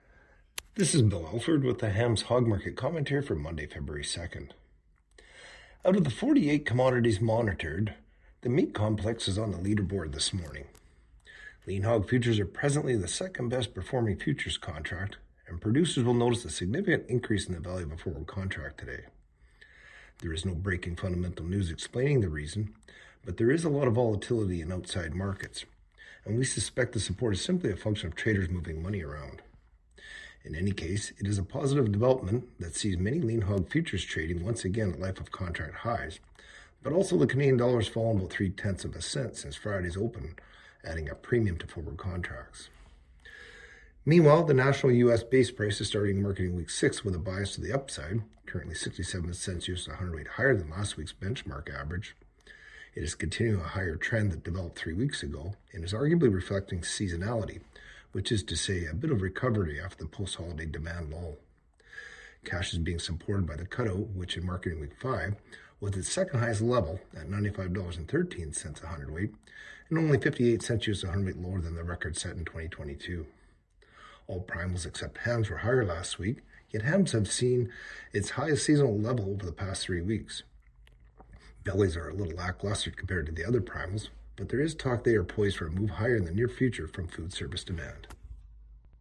Hog-Market-Commentary-Feb.-2-26.mp3